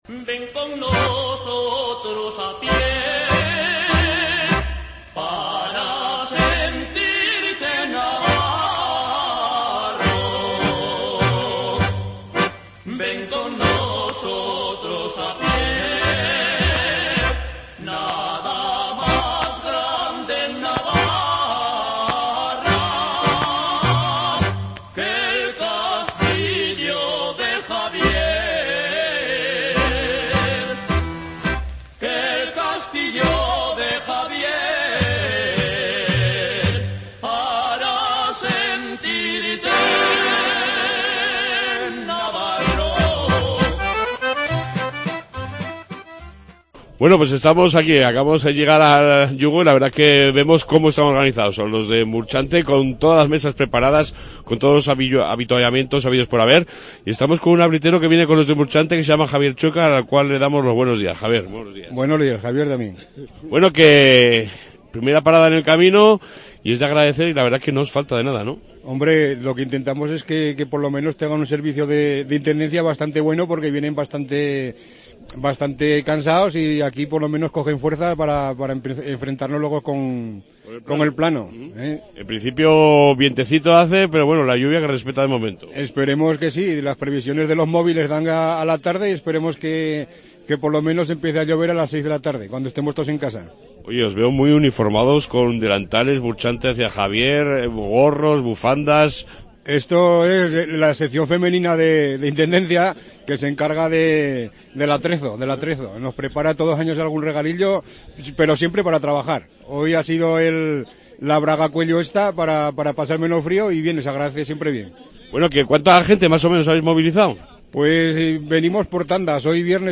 AUDIO: Reportaje que me he traído desde el Yugo con los caminantes hacia Javier...